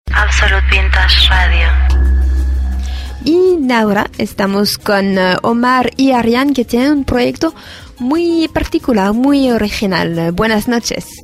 Indicatiu de la ràdio i presentació dels entrevistats